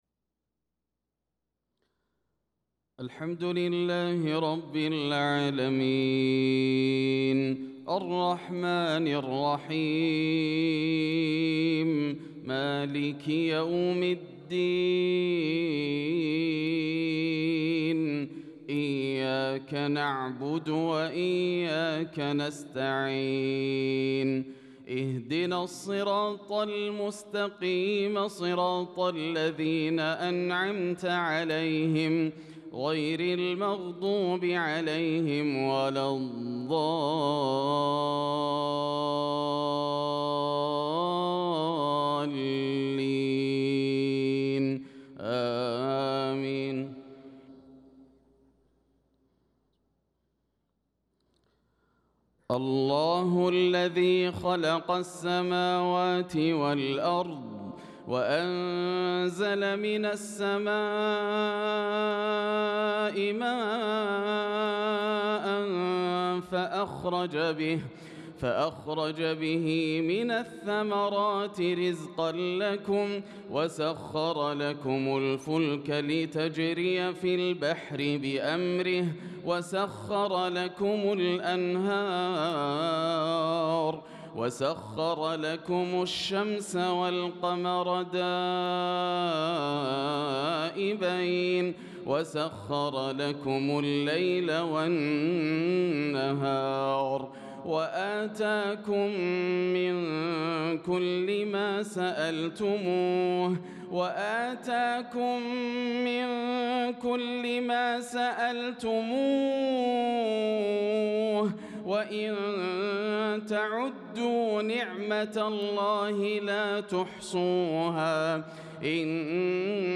صلاة العشاء للقارئ ياسر الدوسري 27 ذو القعدة 1445 هـ